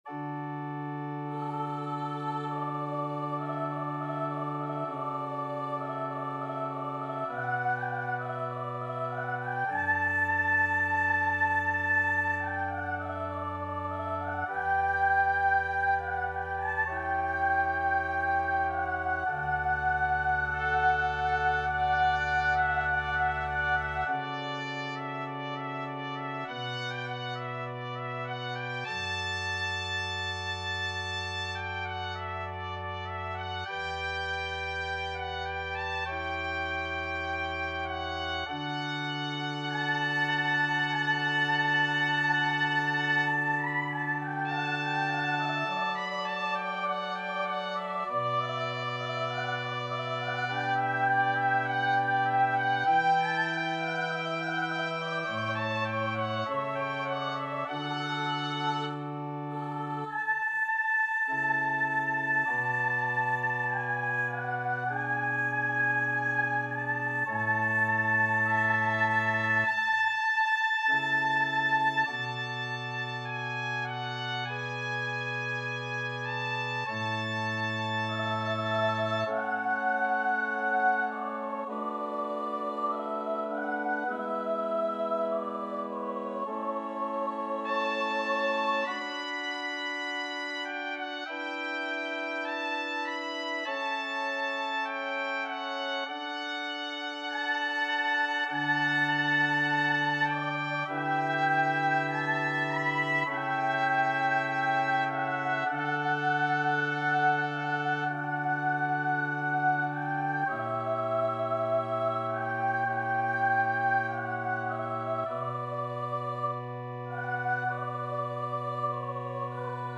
Classical Handel, George Frideric Eternal Source of Light Divine (No. 1 from Ode for the Birthday of Queen Anne, HWV 74) Trumpet version
D major (Sounding Pitch) E major (Trumpet in Bb) (View more D major Music for Trumpet )
Largo
Classical (View more Classical Trumpet Music)